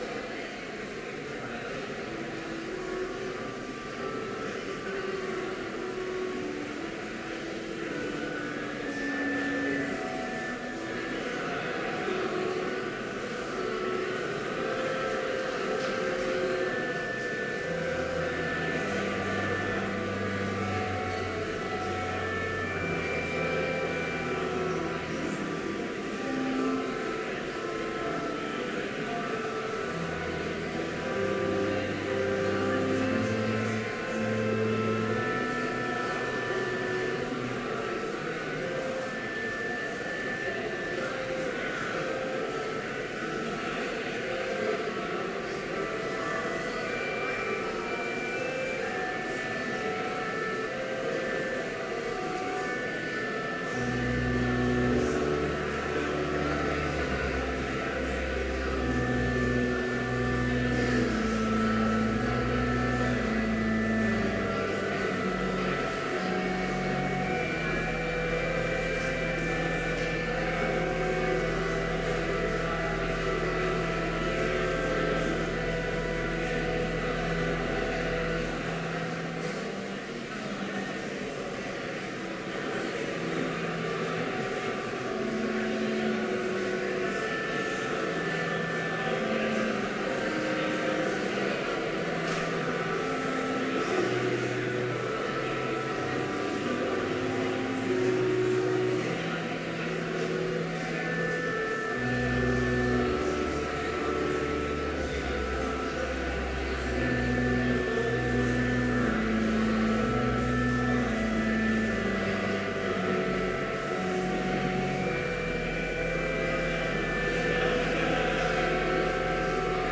Complete service audio for Chapel - September 3, 2014
Order of Service Prelude Hymn 453, Out of the Deep I Call Reading: Luke 18:9-14 Homily Prayer Hymn 586, How Blest Are They Who Hear God's Word Benediction Postlude